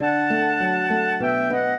flute-harp
minuet8-11.wav